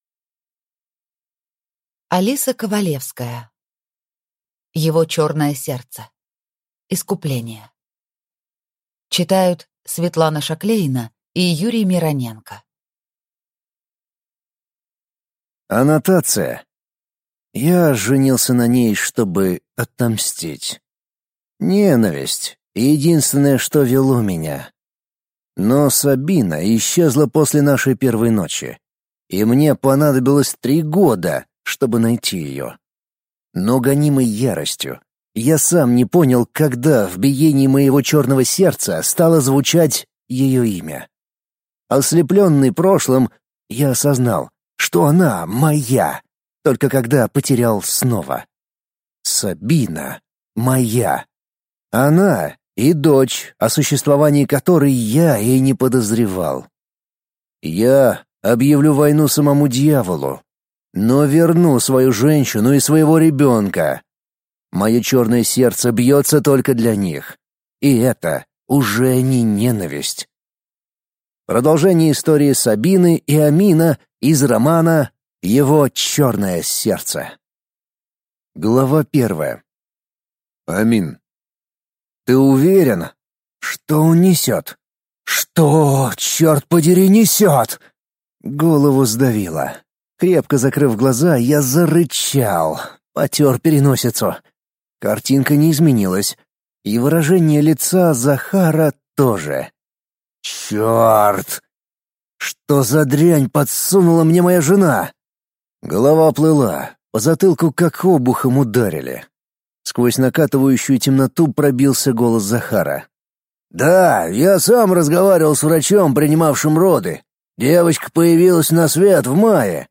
Аудиокнига Его чёрное сердце. Искупление | Библиотека аудиокниг